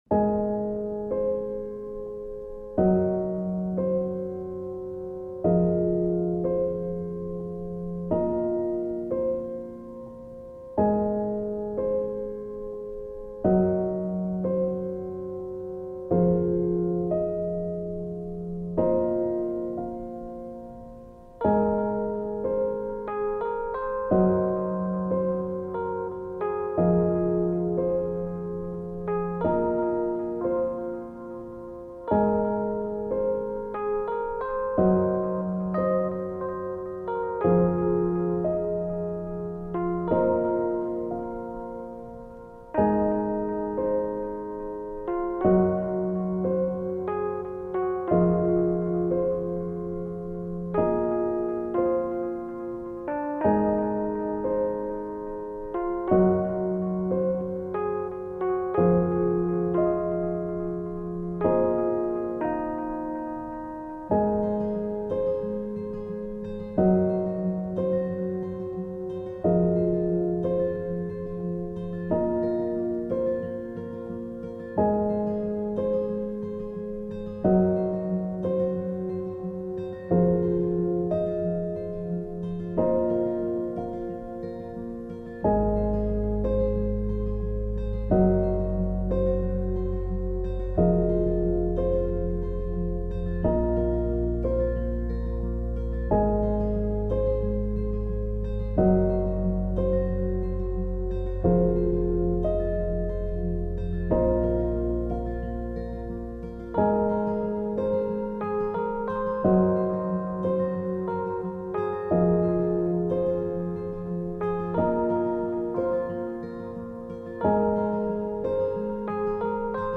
Town's Night Time - Piano
Track: 6 Title: Town's Night Time Genre: Relaxing, Ambient Feel free to use my music, it would be great if you credit me.